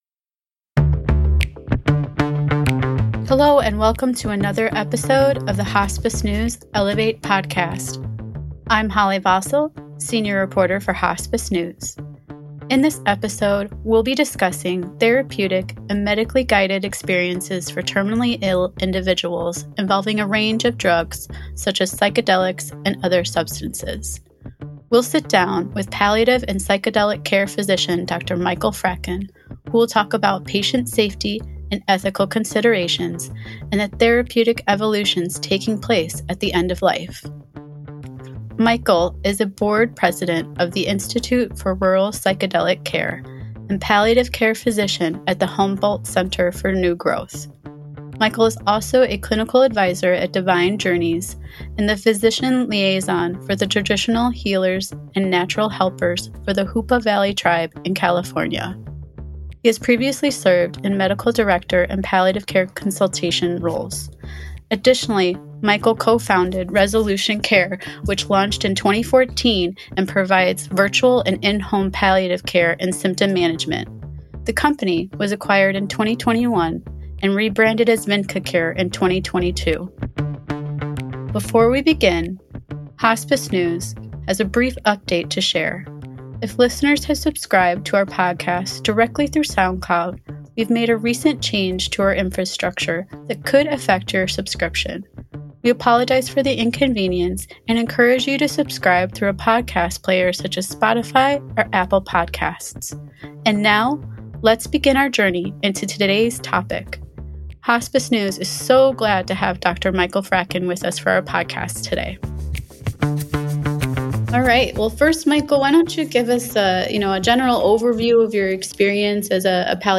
Exploring Ethical Unknowns of End-of-Life Psychedelic Therapies Hospices need a deep understanding of the ethical considerations involved in providing therapeutic- and medically-guided patient experiences that include psychedelics and other controlled substances. In this episode of ELEVATE, Hospice News speaks with palliative and psychedelic care physician